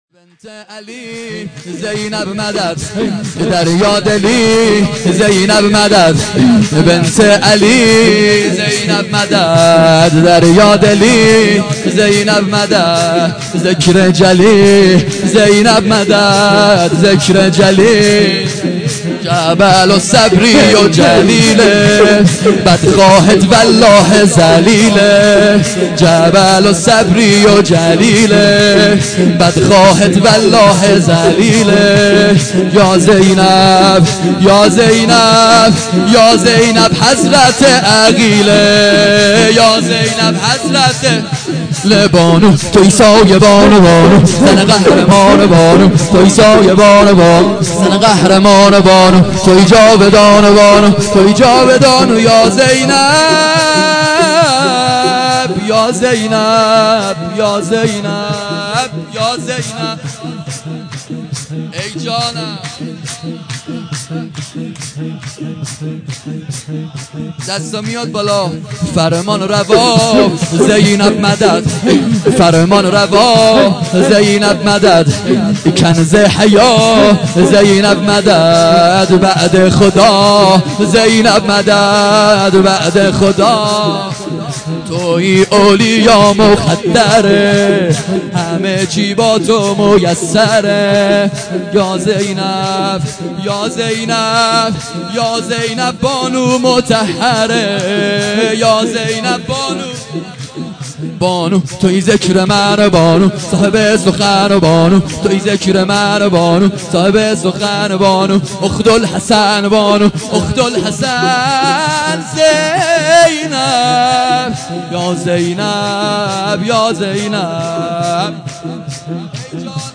خیمه گاه - علمدار - سرود بنت علي زينب مدد